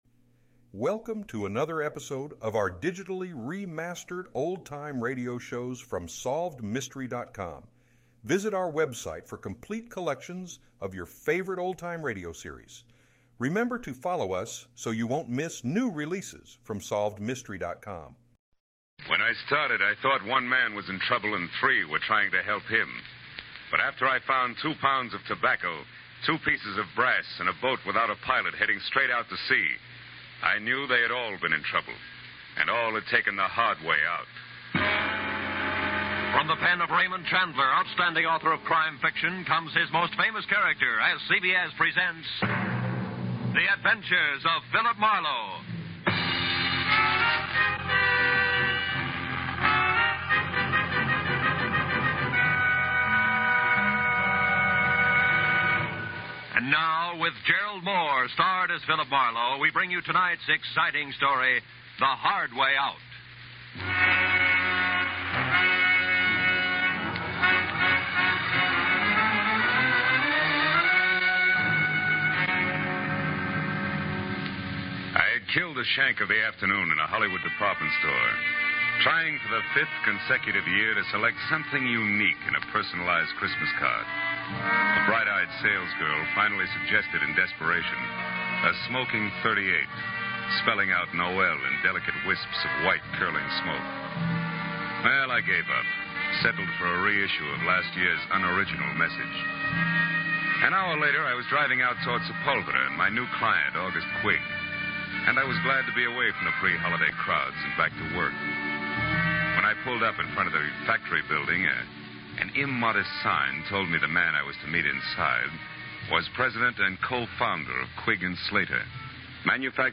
Gerald Mohr starred as Marlowe, and the show was known for its gritty and realistic portrayal of crime and the criminal underworld.
In addition to Mohr's excellent portrayal of Marlowe, the show also featured a talented supporting cast of actors and actresses, including Howard McNear and Parley Baer.